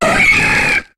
Cri de Scarhino dans Pokémon HOME.